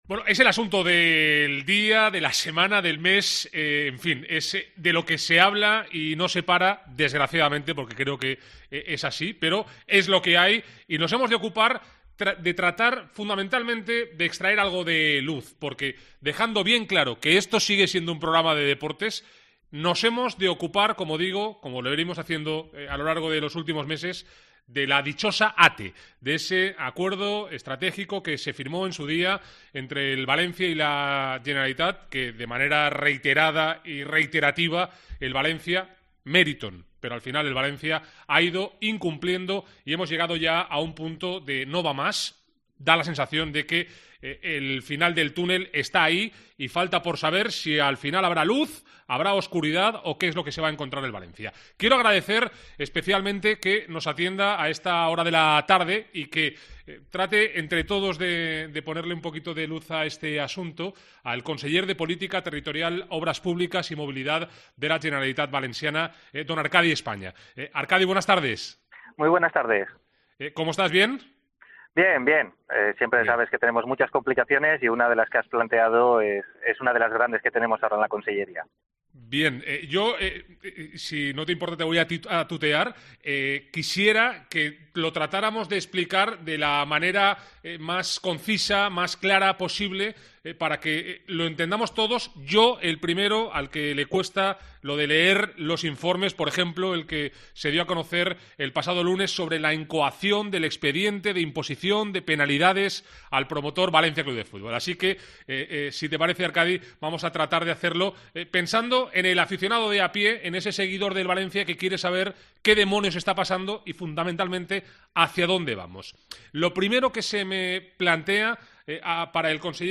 AUDIO. Entrevista al conseller España en Deportes COPE Valencia